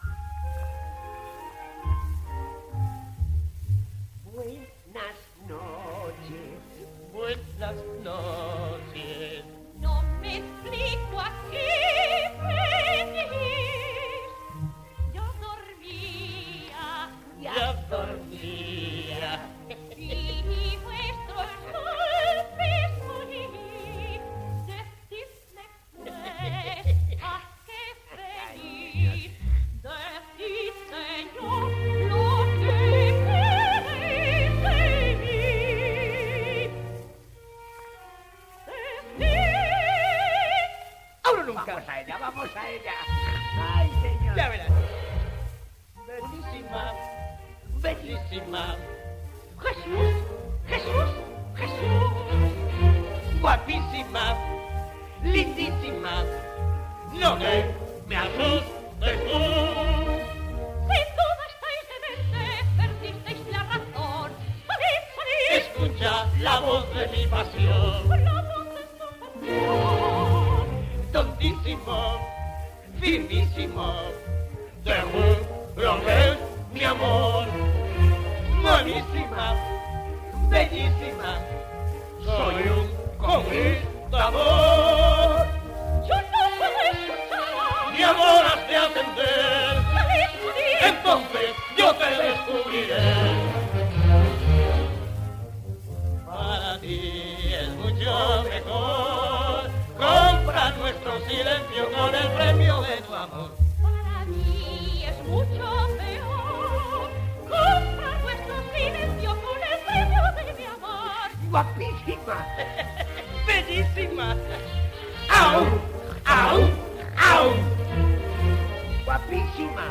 Terceto cómico ("Buenas noches.